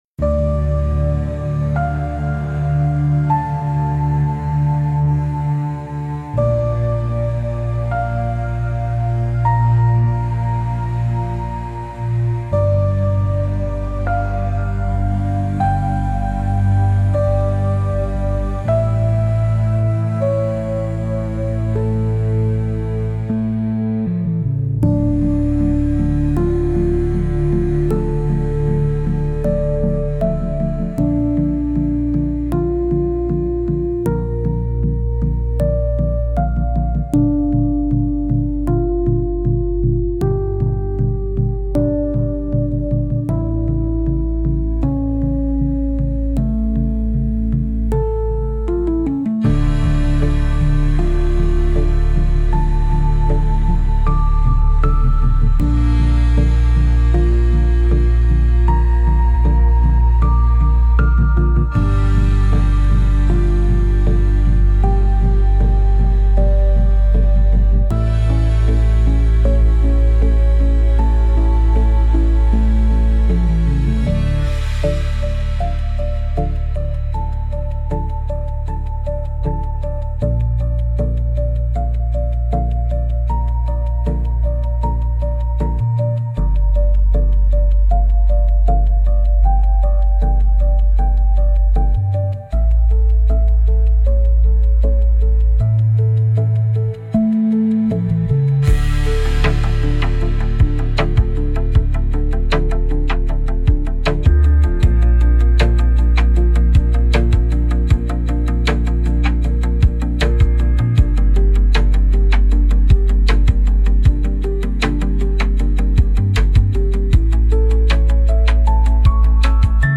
Instrumental- Dishonor Wakes Softly - 4 Mins